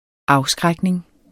Udtale [ -ˌsgʁagneŋ ]